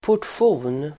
Uttal: [por_tsj'o:n]